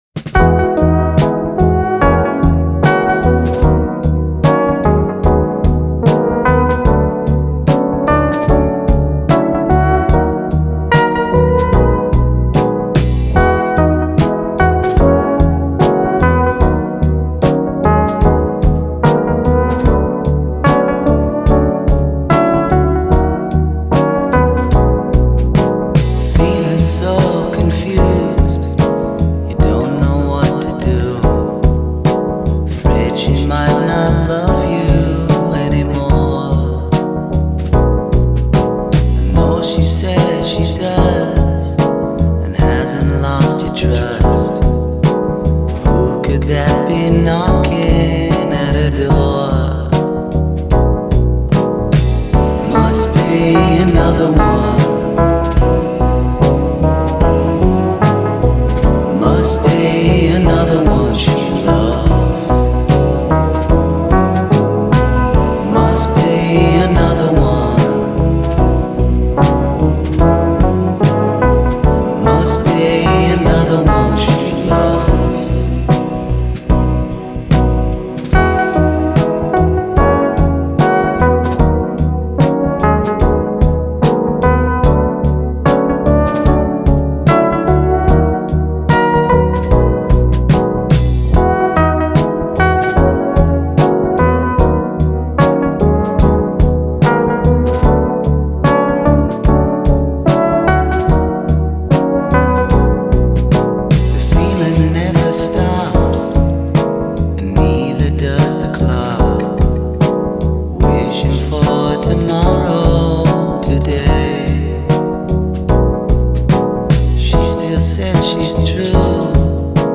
погружает слушателя в атмосферу ностальгии и меланхолии